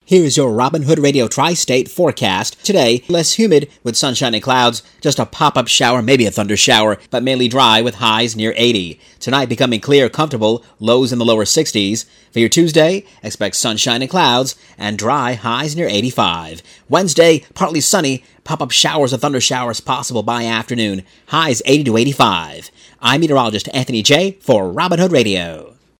Your Robin Hood Radio Tri-State Forecast
7493-WHDD-MONDAY-WEATHER.mp3